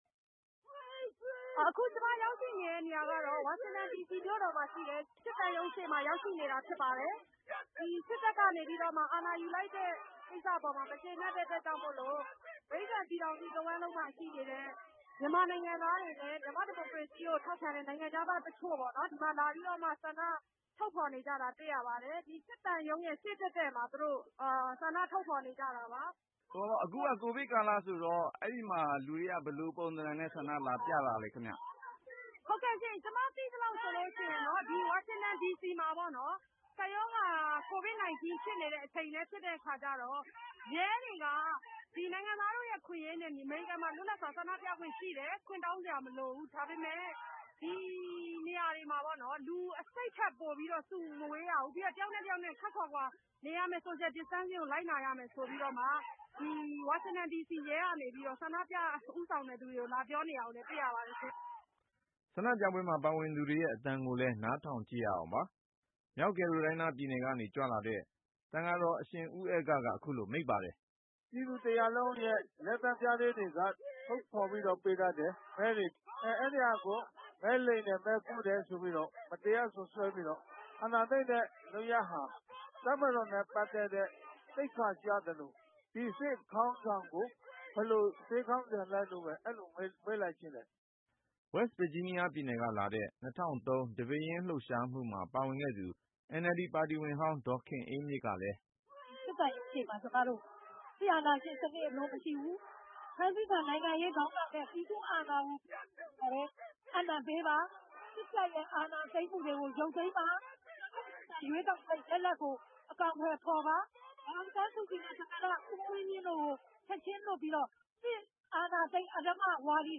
ဆန္ဒပြပွဲမှာ ပါဝင်သူတွေရဲ့အသံကိုလည်း နားထောင်ကြည့်ရအောင်ပါ။